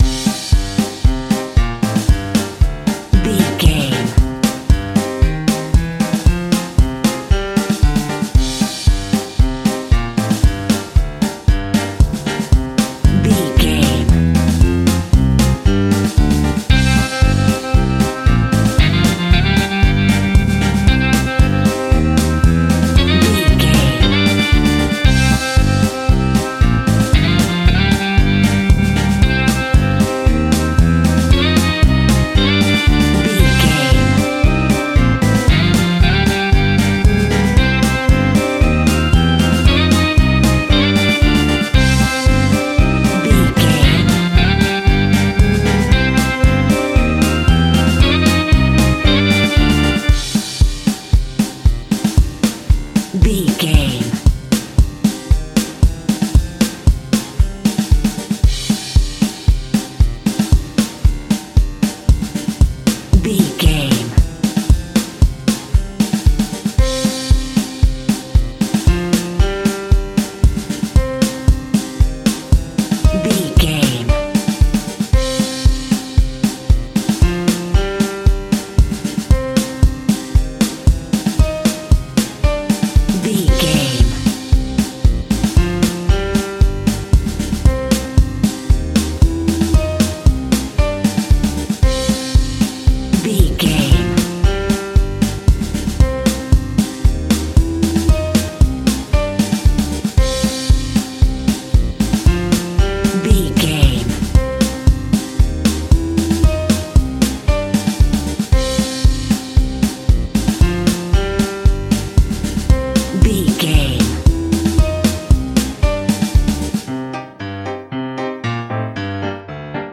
Aeolian/Minor
ominous
dark
haunting
eerie
energetic
groovy
drums
electric guitar
bass guitar
piano
synthesiser
creepy
Horror Synths